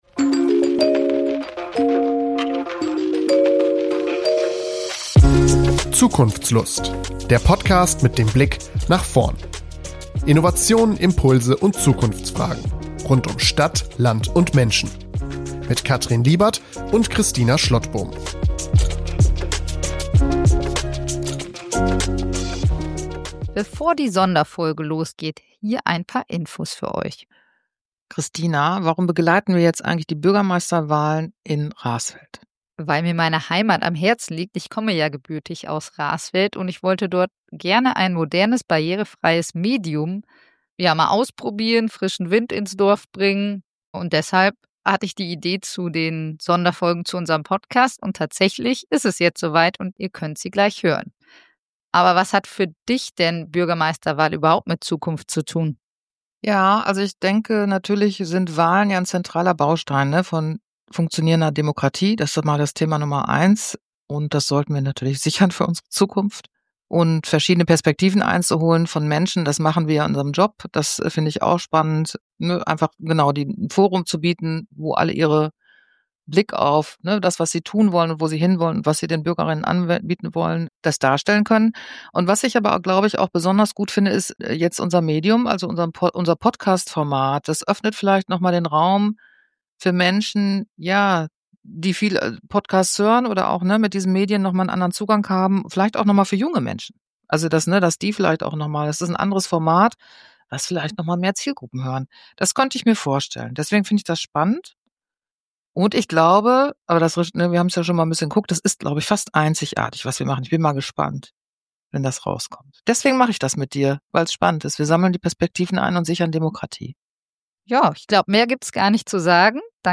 Wir sprechen mit ihm über echte Bürgerbeteiligung für Jung und Alt, bezahlbaren Wohnraum als Standortfaktor, digitale Entwicklungen und seine Sicht auf Kommunalpolitik als Antwort auf Politikverdrossenheit. Auch seine persönliche Motivation und Zukunftsvision für Raesfeld 2030 kommen nicht zu kurz.